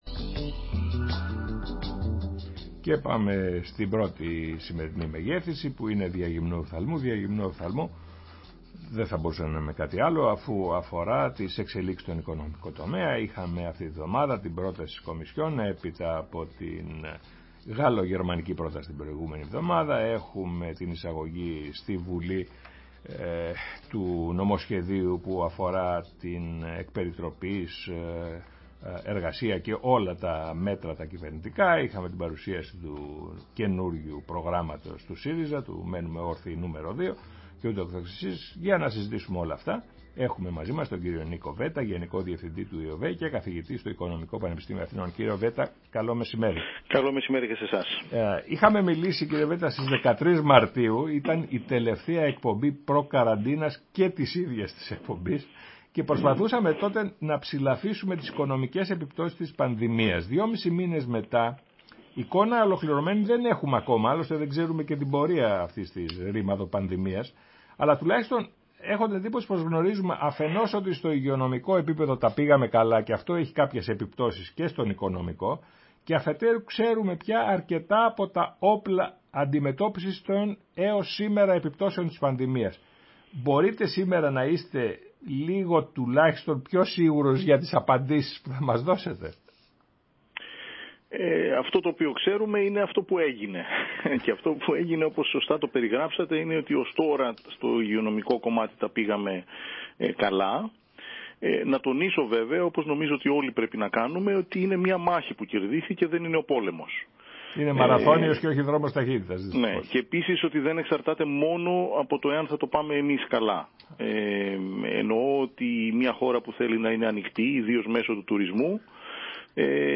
Συνέντευξη στο ραδιόφωνο της ΕΡΤ